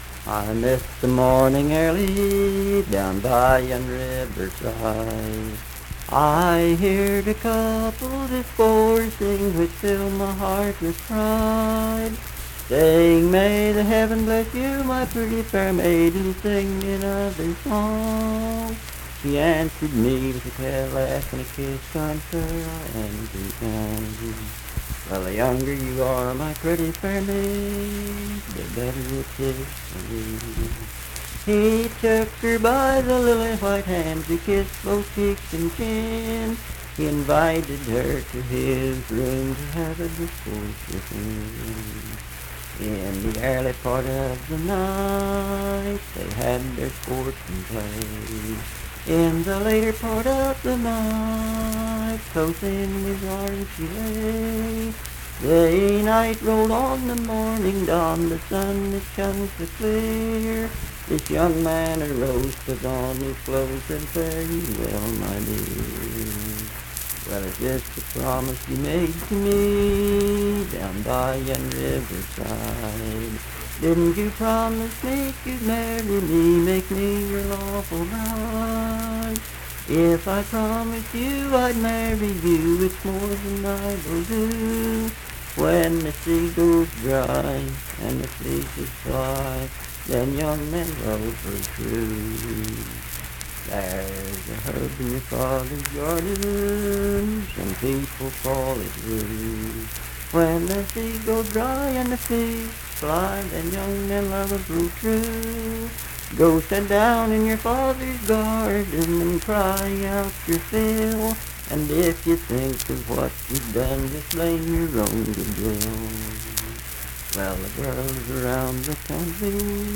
Unaccompanied vocal music
Verse-refrain 6d(4).
Voice (sung)
Braxton County (W. Va.)